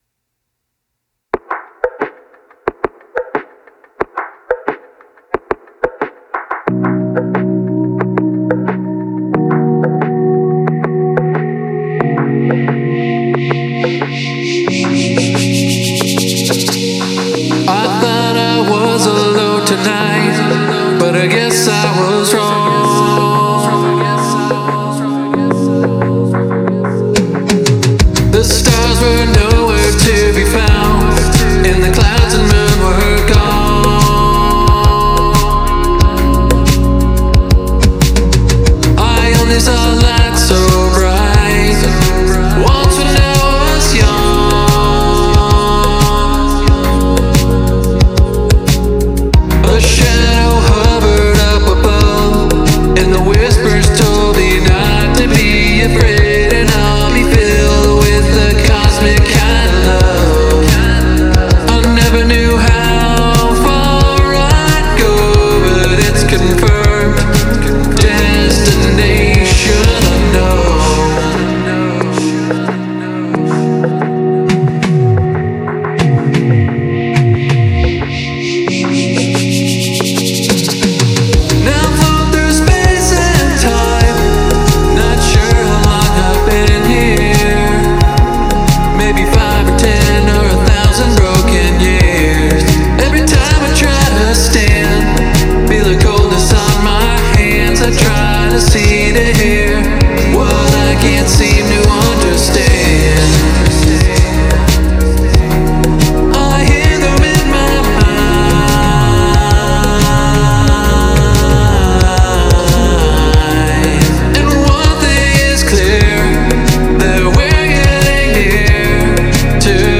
Nostalgic and atmospheric
while still maintaining a modern synthpop vibe.